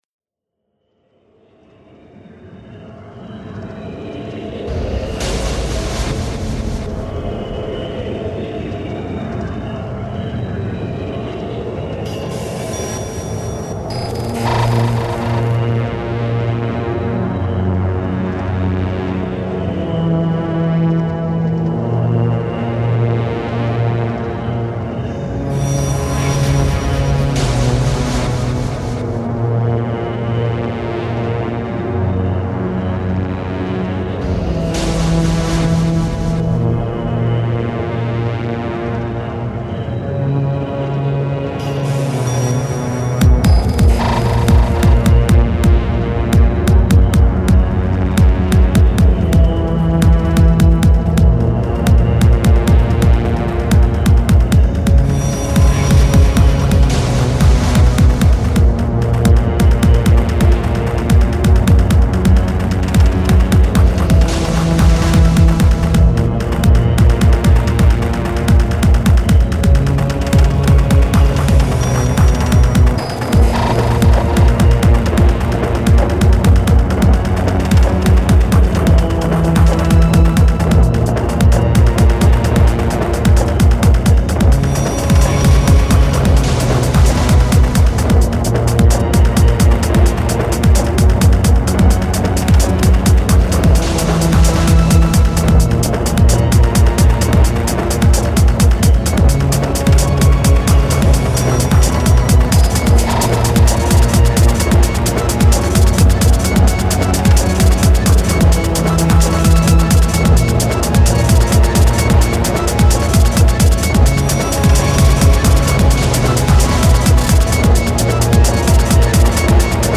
dance/electronic
Deep and slow building, Industrial techno
Techno
Ambient
Breaks & beats